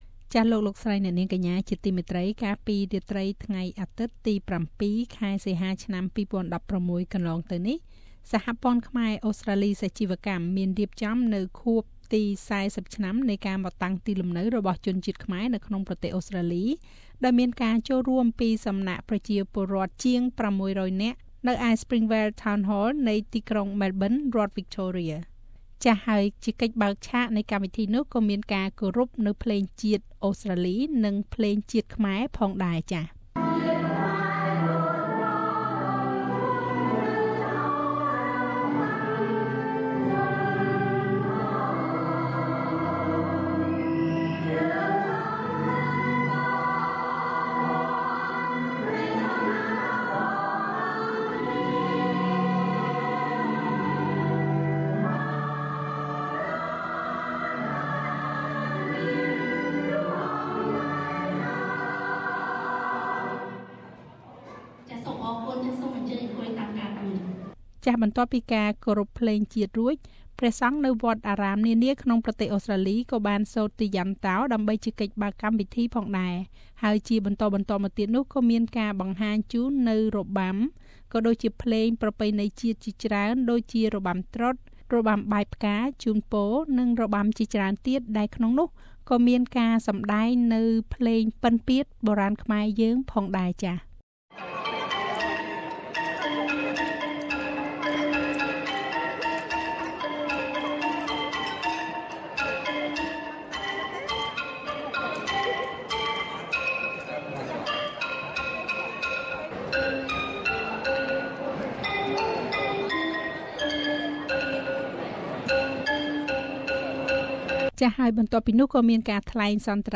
សហព័ន្ធខ្មែរអូស្រ្តាលីសាជីវកម្មបានរៀបចំពិធីជប់លៀងខួបទី៤០ឆ្នាំនៃការមកតាំងទីលំនៅរបស់ជនជាតិខ្មែរនៅប្រទេសអូស្រ្តាលីកាលពីយប់ថ្ងៃអាទិត្យទី៧សីហាឆ្នាំ២០១៦ ដោយមានការចូលរួមគាំទ្រពីប្រជាជនខ្មែរយើងប្រមាណជាង៦០០នាក់។សូមស្តាប់នូវកម្មវិធីសំខាន់ៗនិងទស្សនាការសម្តែងរបាំនារាត្រីនោះដូចតទៅ៖